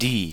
Ääntäminen
Synonyymit dieser jener Ääntäminen stressed: IPA: /diː/ unstressed: IPA: /dɪ/ Haettu sana löytyi näillä lähdekielillä: saksa Käännös Pronominit 1. joka 2. jotka 3. se 4. ne Muut/tuntemattomat 5. mikä 6. jonka 7. jollekulle Artikkeli: die .